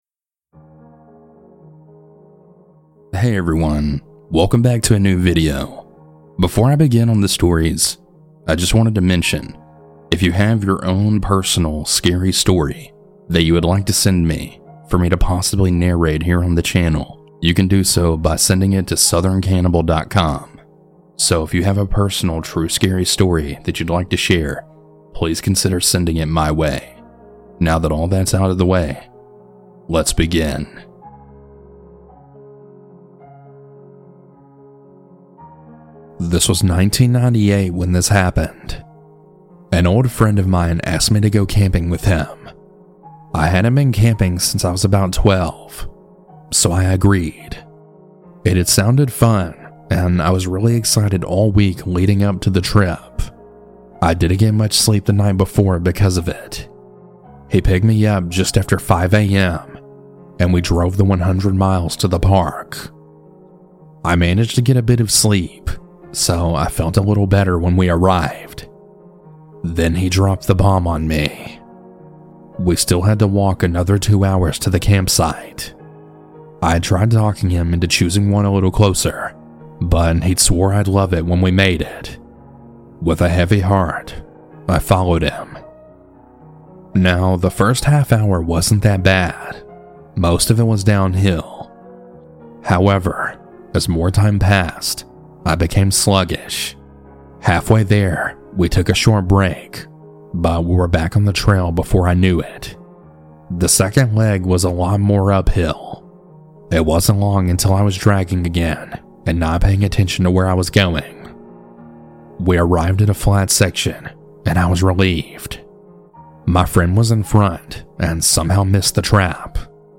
- Anonymous Huge Thanks to these talented folks for their creepy music!